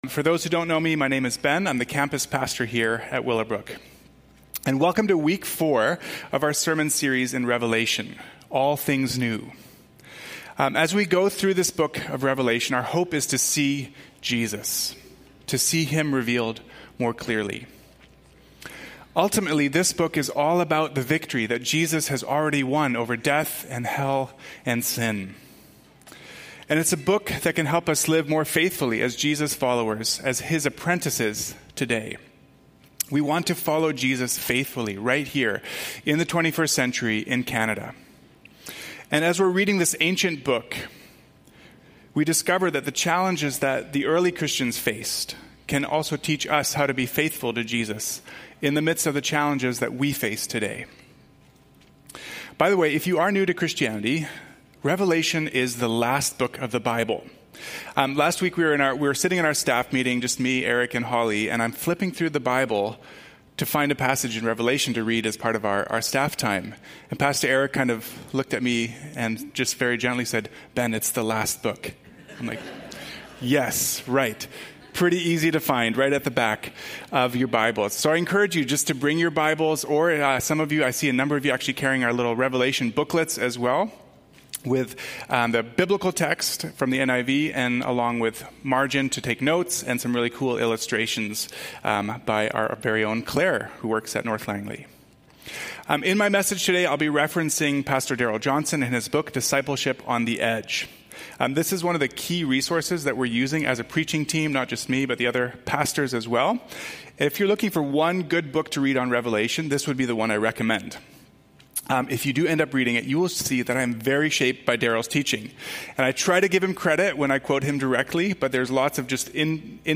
Willowbrook Sermons | North Langley Community Church